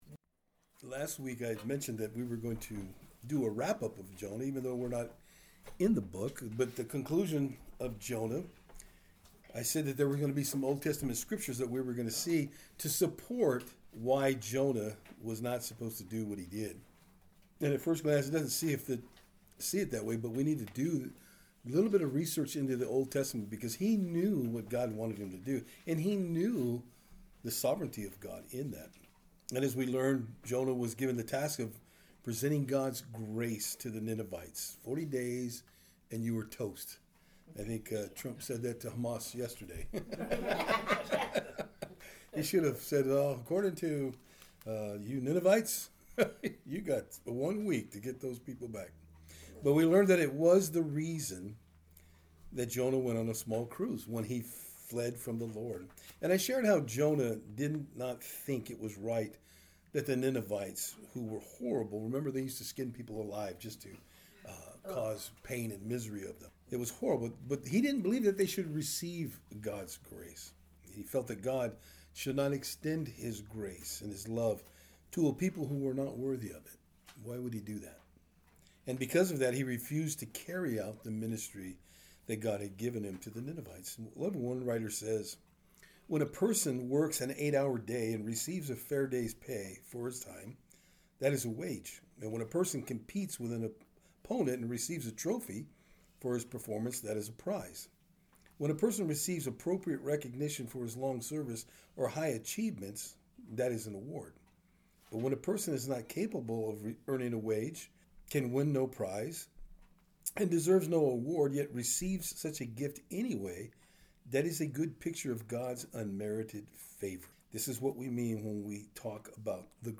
Various O.T Scriptures Service Type: Thursday Afternoon Today we will be looking at the final segment of our study in the Book of Jonah.